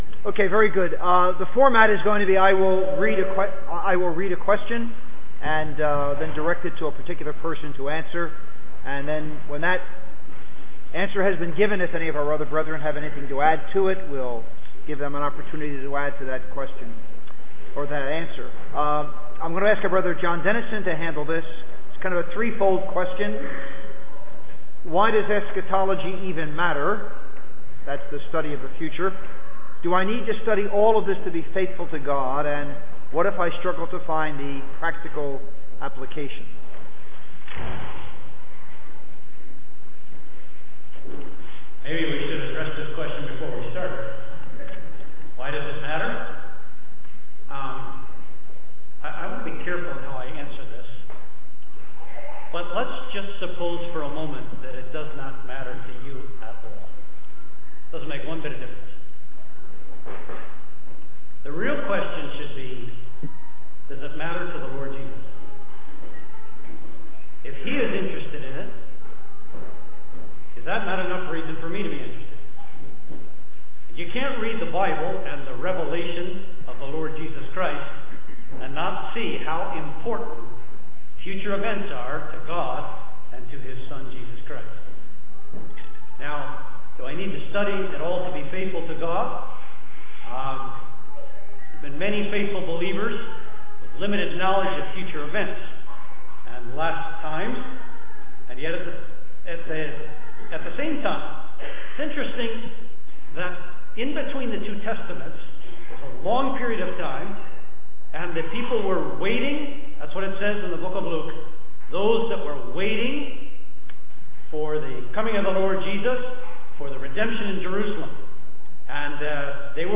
Q&A – 2018 Conference – Pennsauken Gospel Hall
Q-and-A-Session.mp3